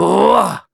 Kibera-Vox_Casting2.wav